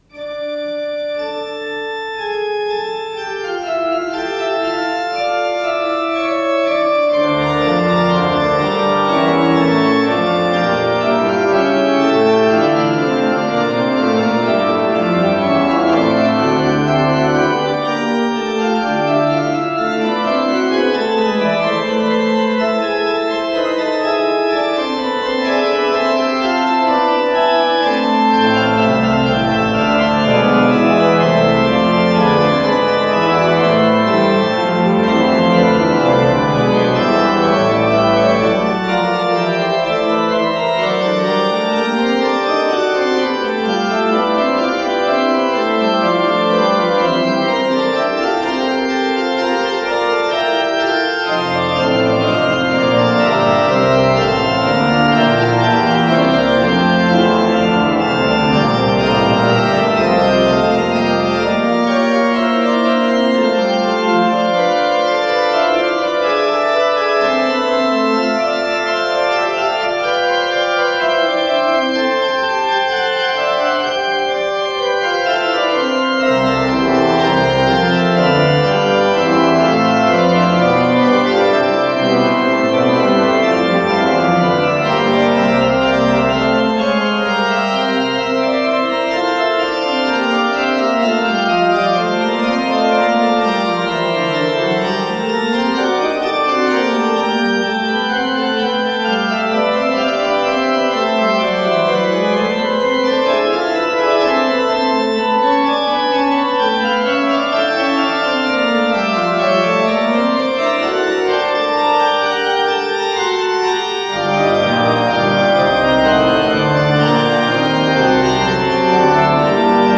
с профессиональной магнитной ленты
ИсполнителиГарри Гродберг
ВариантДубль моно